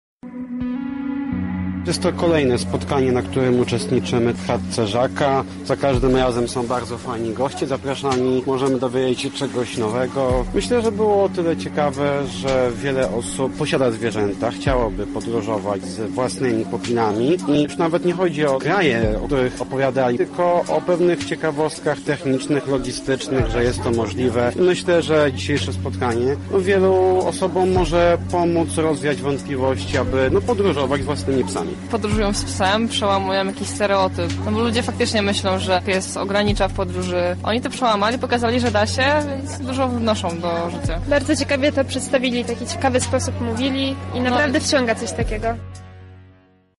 Nasz reporter zapytał uczestników o wrażenia ze spotkania:
Uczestnicy